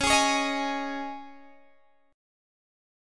Dbsus2 chord